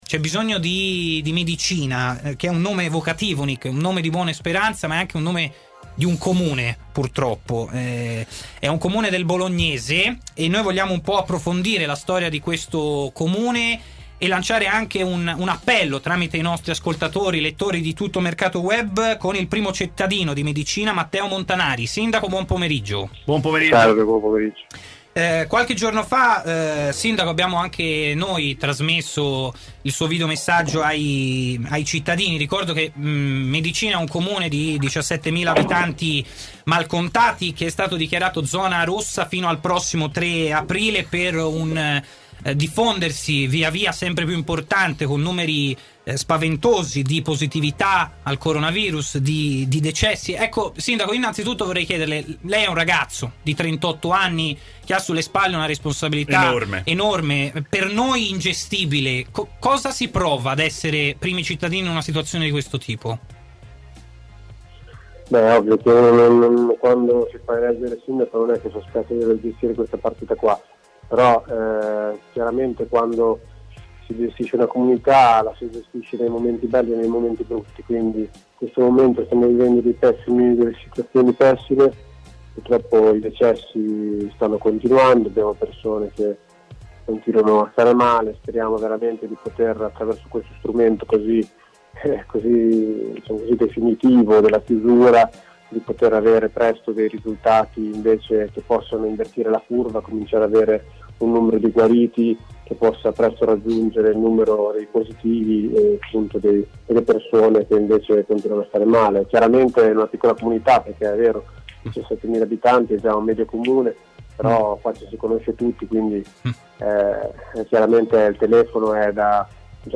Matteo Montanari, sindaco di Medicina comune della provincia di Bologna, interviene a “Stadio Aperto” su TMW Radio per parlare della grave situazione del paese emiliano.